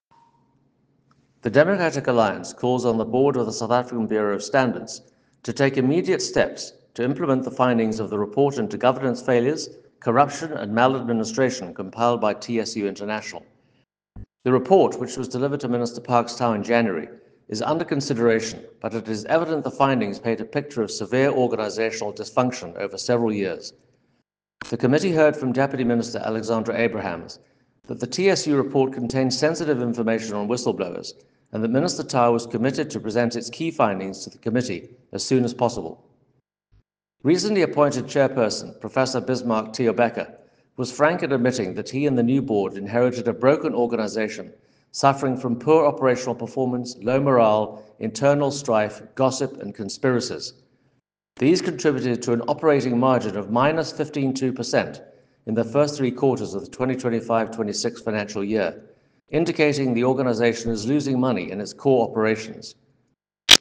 soundbite by Toby Chance MP.